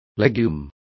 Complete with pronunciation of the translation of legume.